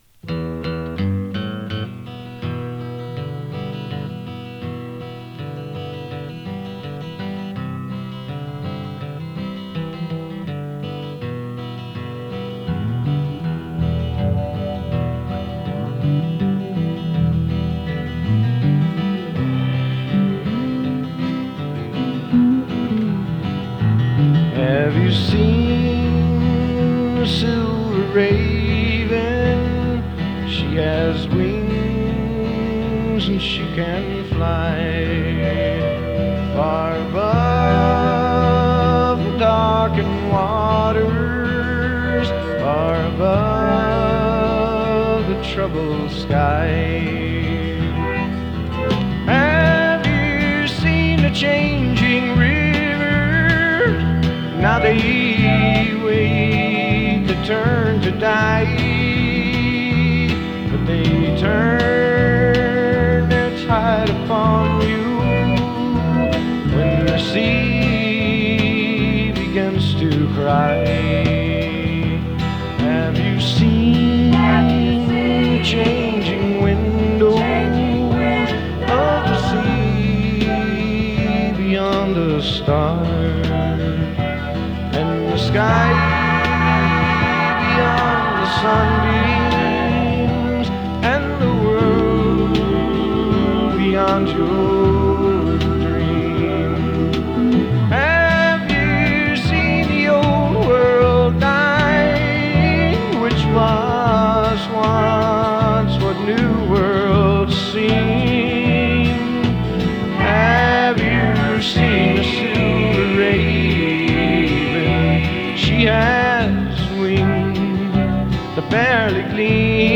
アシッド カントリー フォーク ファンク